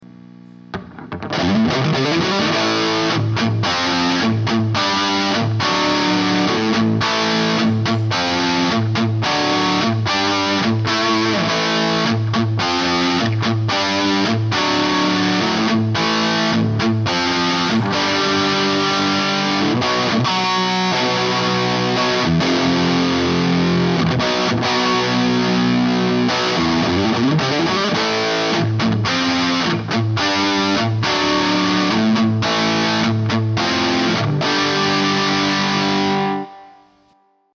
Guitar: Ibanez RG570 - Bridge: Duncan Distortion - Neck:Ibanez C2 single coil
Speaker: 1978 Peavey 412 with 200 watt Peavey Scrpions - 4 ohms
SM57 -> Audiobox USB -> Cubase LE4
Sounds much tighter now. Really has that 80s feel. 8)
Cascade: on